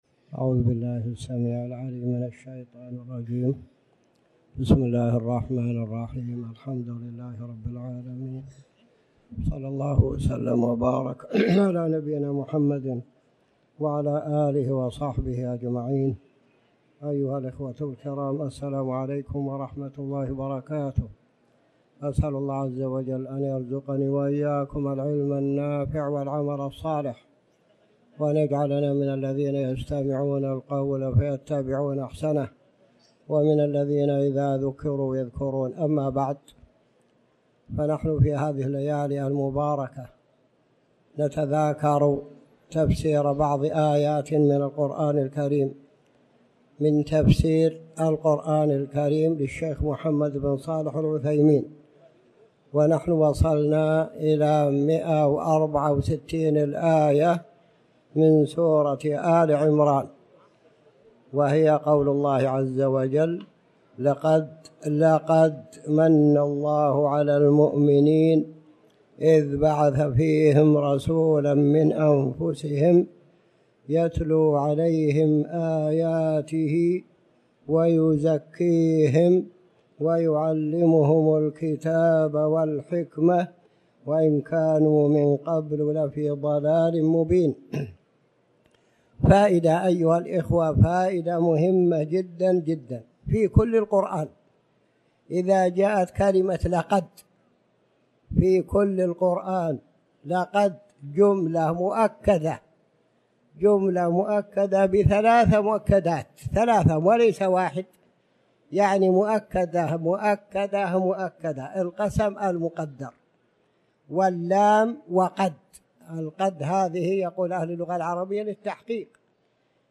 تاريخ النشر ١٣ ربيع الأول ١٤٤٠ هـ المكان: المسجد الحرام الشيخ